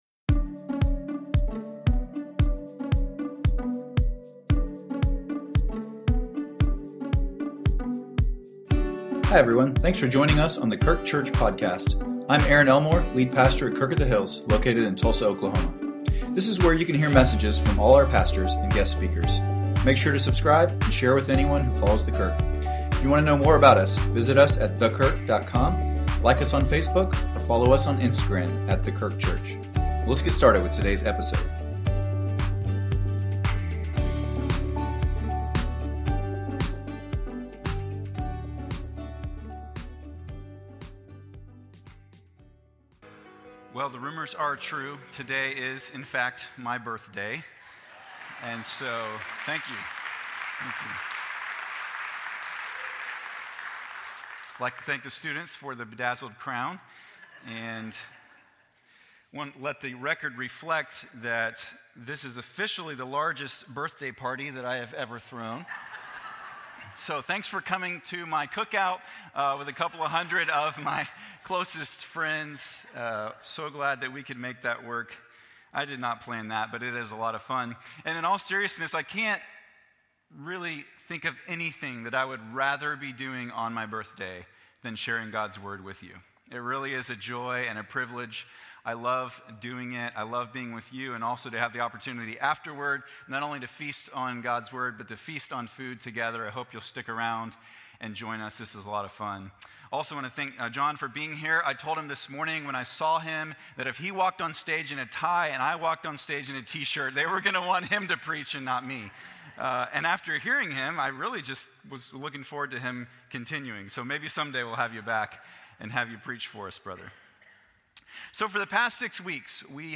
A message from the series "Faithful in the Fray."